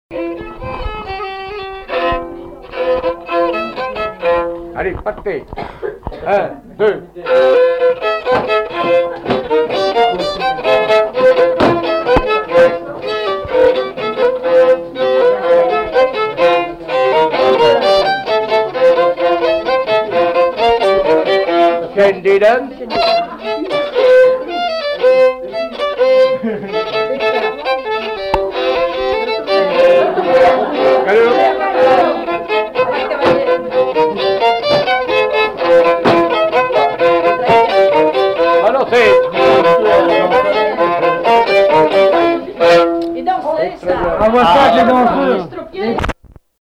Localisation Chantonnay
Résumé instrumental
danse : quadrille : galop
répertoire d'airs de danse au violon